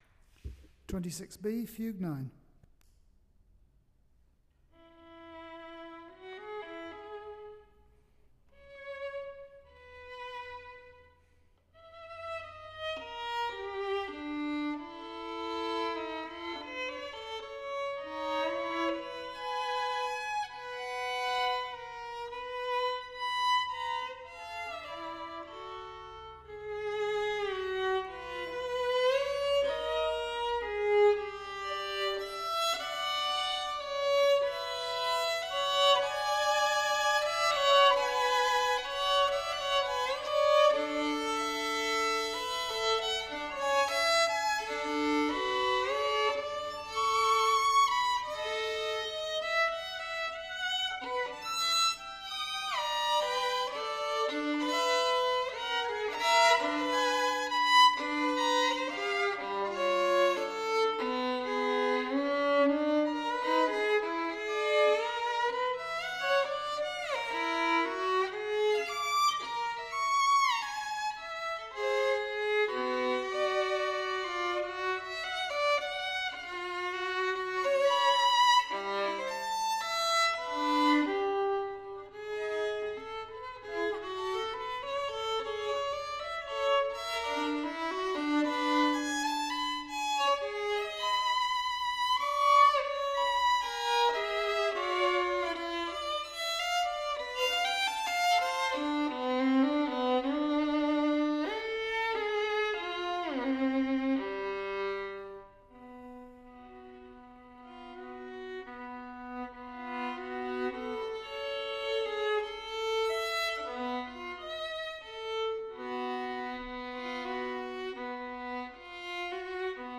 Here is an outtake from one of them